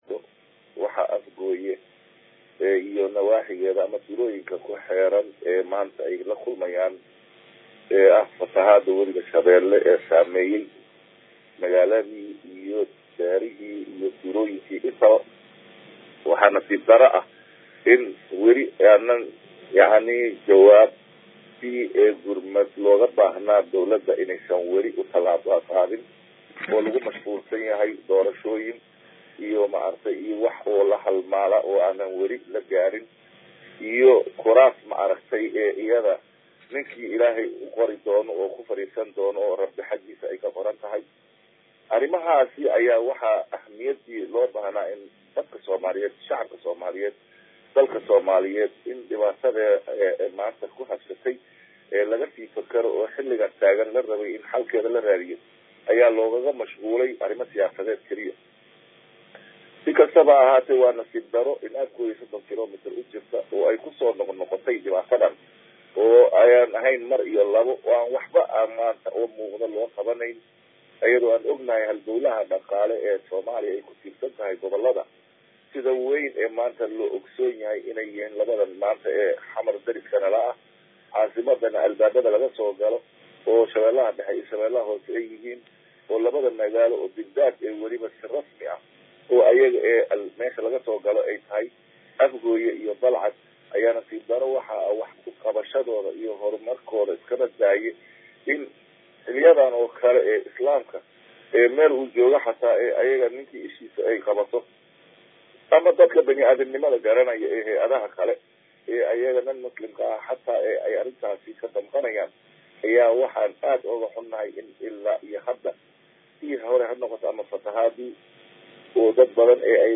Dhageyso: Xildhibaan Dalxa oo ka hadlay Fatahaada Wabiga shabeele uu ka geystay Degmada Afgooye